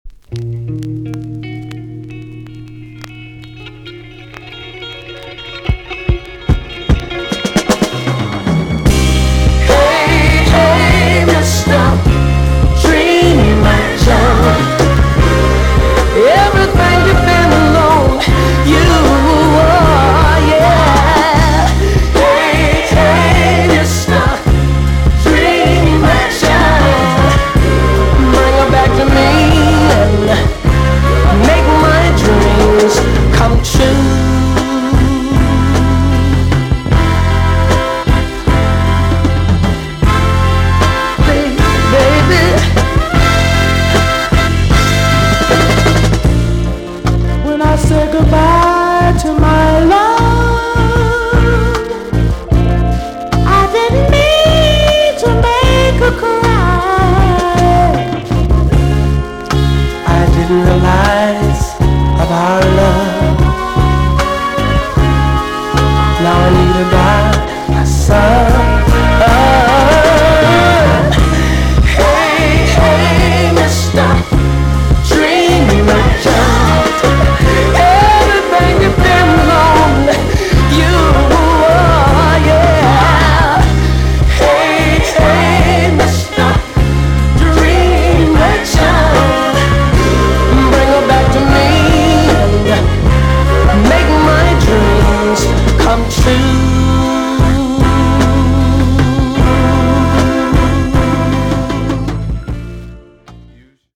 EX-~VG+ 少し軽いチリノイズがありますがキレイです。
1975 , WICKED JAMAICAN SOUL TUNE RECOMMEND!!